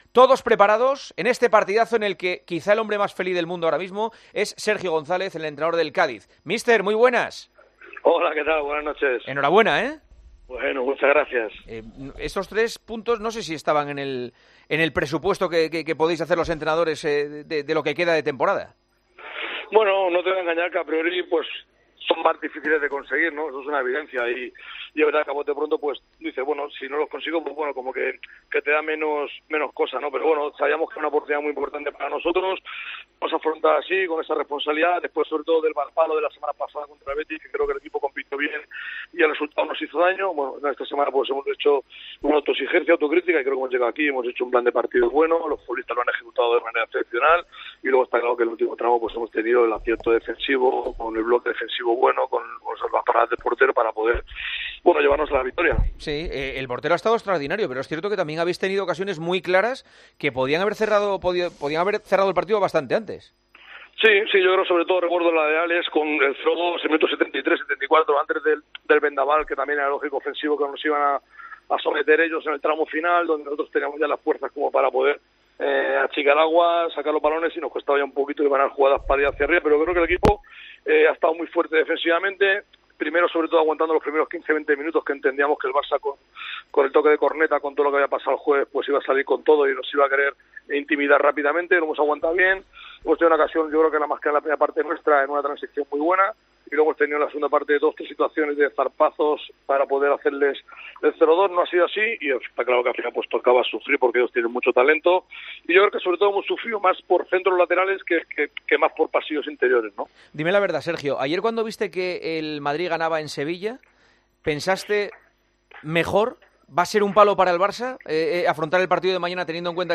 Hablamos con el entrenador del Cádiz, Sergio González, de la victoria en el Camp Nou que acerca al equipo a seguir un año más en Primera.